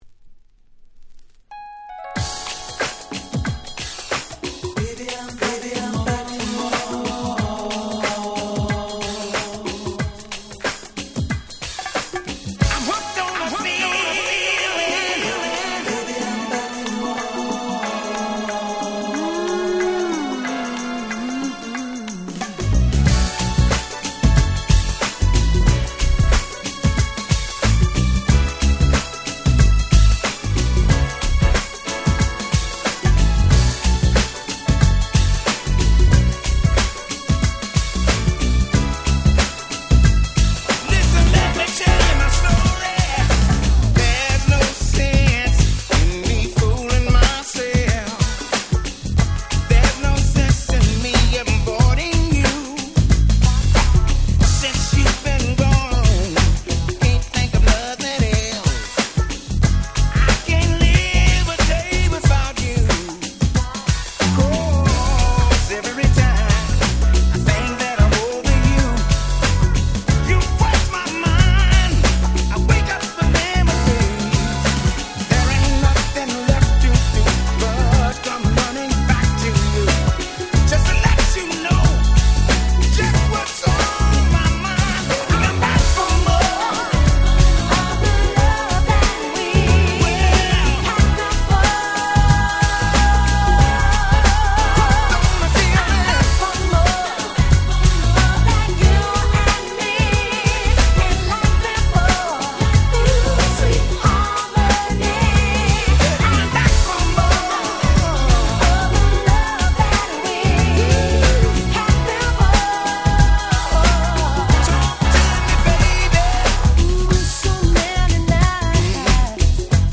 93' Super Nice Cover UK Soul !!